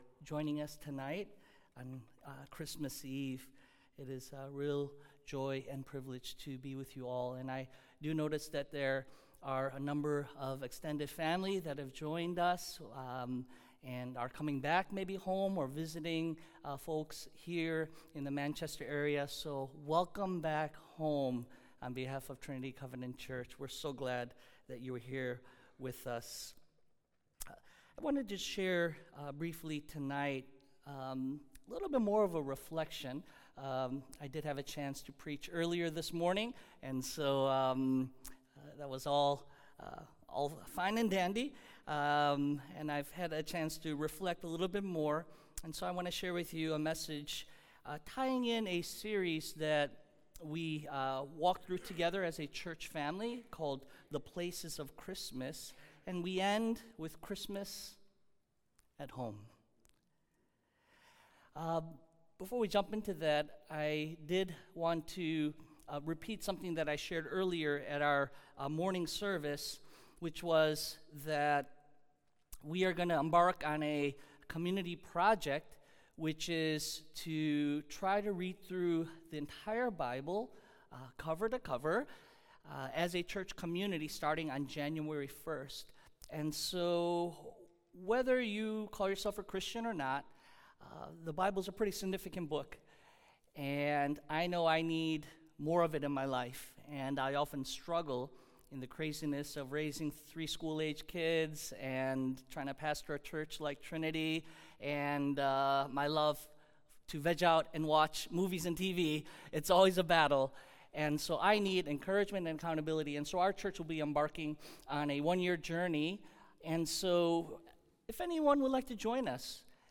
"Christmas at Home" - Candlelight Service